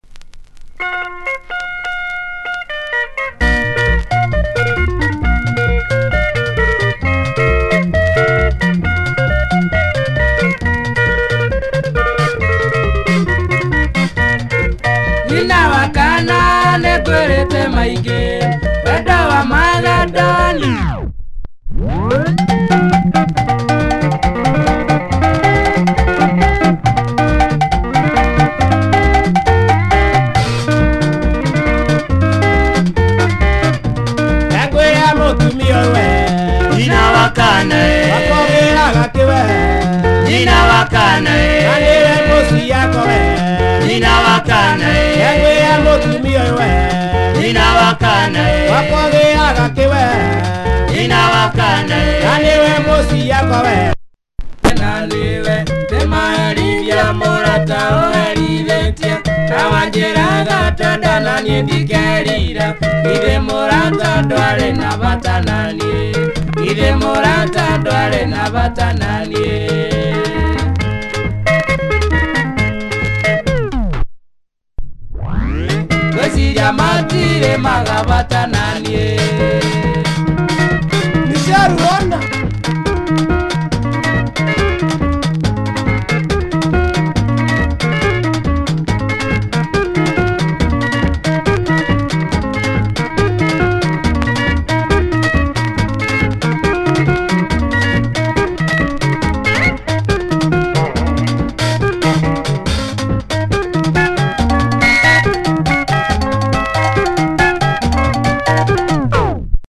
Quality Kikuyu Benga